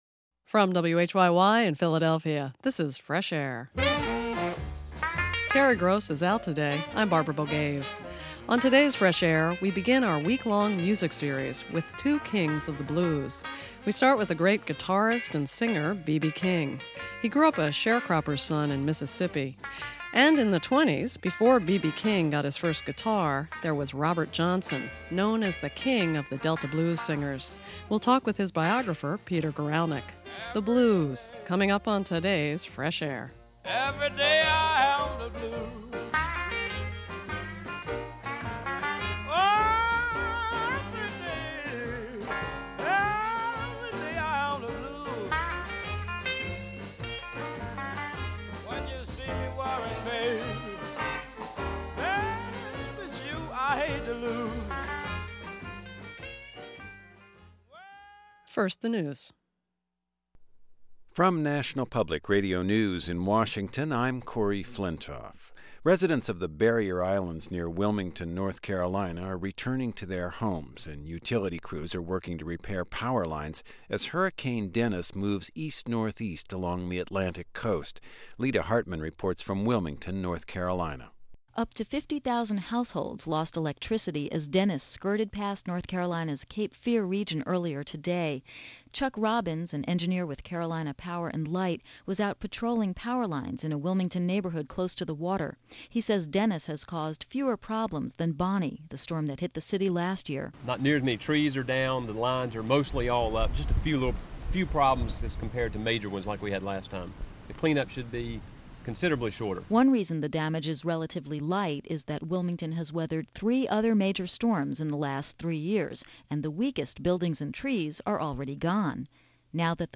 In his biography, entitled "Blues All Around Me"(Avon Books) he recounts his life from his early days in Mississippi, to breaking into the music business in Memphis, to his career today. Terry Gross talked to him just after his book was published.